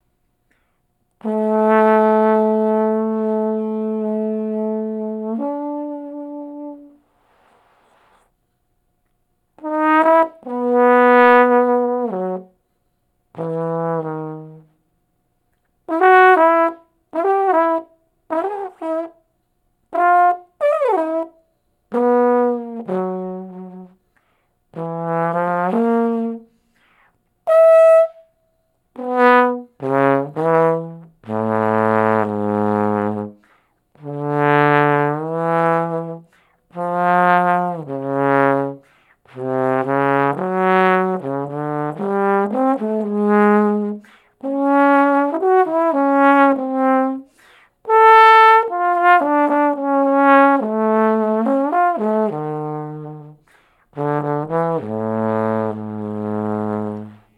trombone, guitar, vocals, keyboards, aux percussion
bass, vocals, probably aux percussion